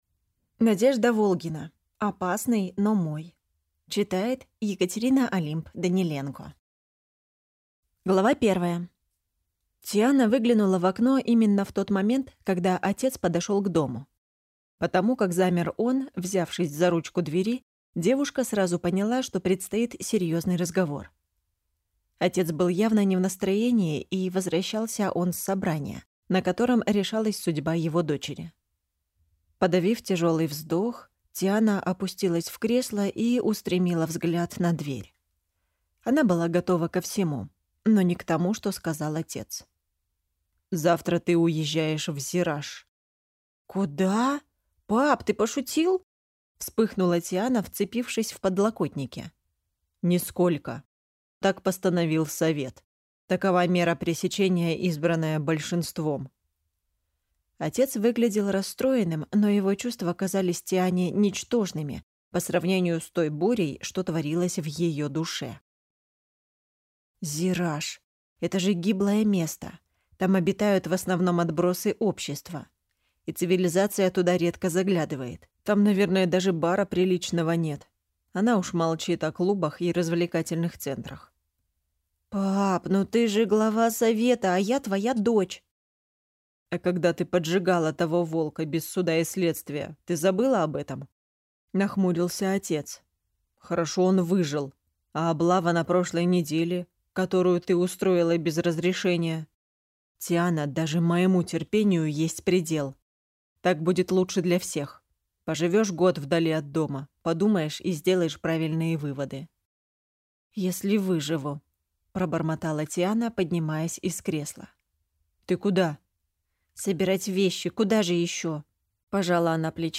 Аудиокнига Опасный, но мой | Библиотека аудиокниг